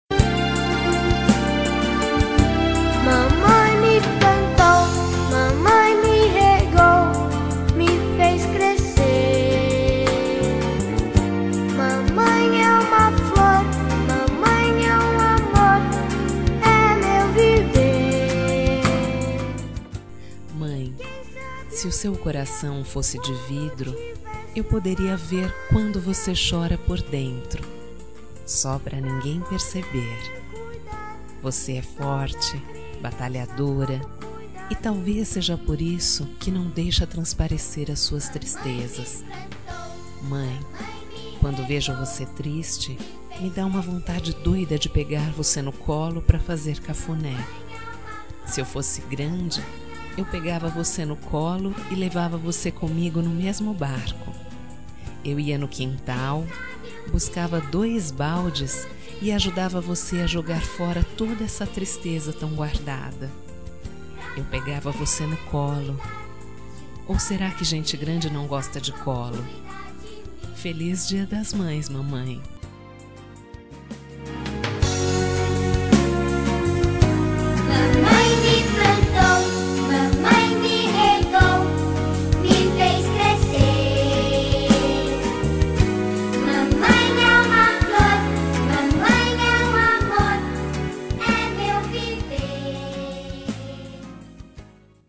Voz Menina